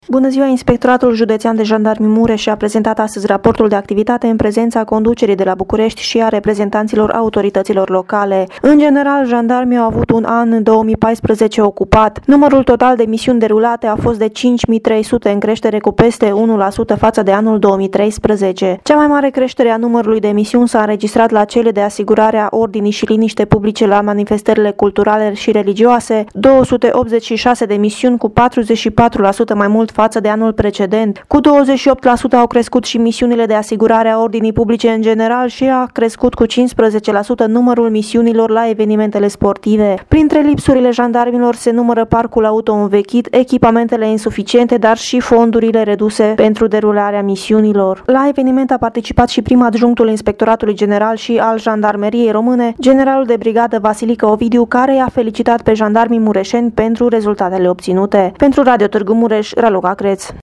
relatare.mp3